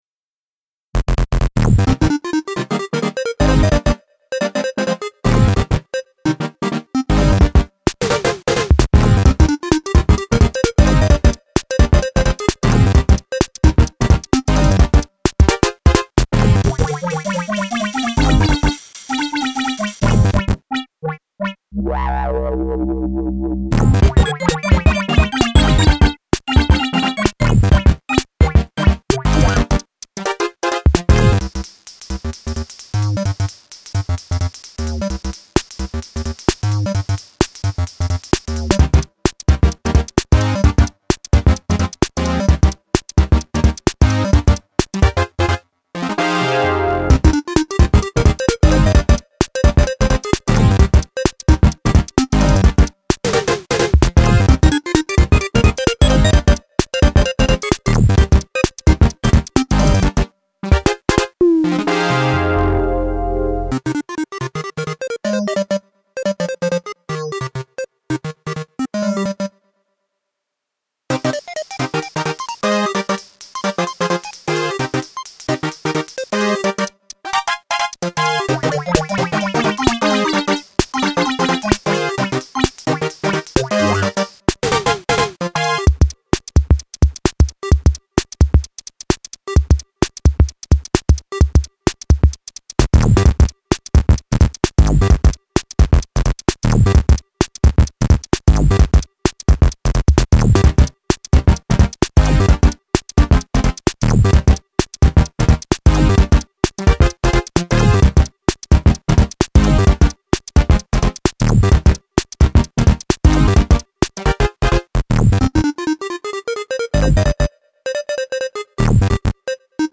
Jumpy and groovy 8-bit electronica.